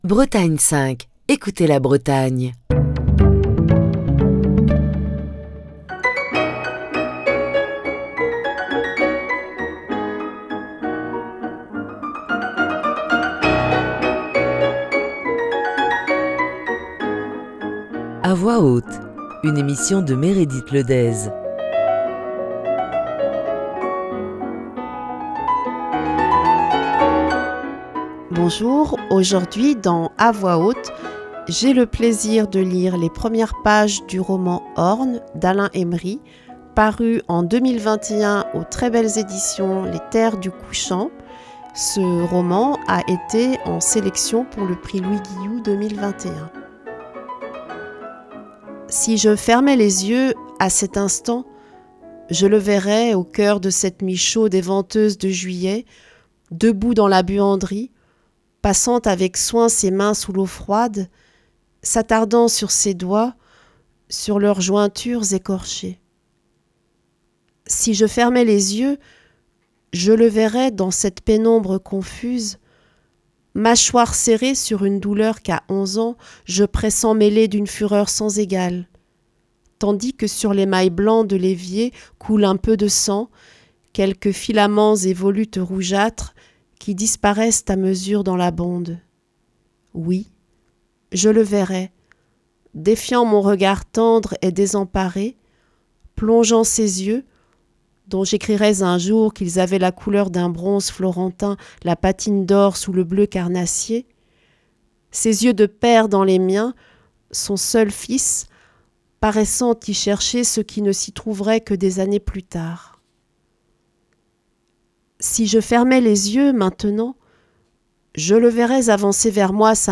lit un extrait du roman d'Alain Emery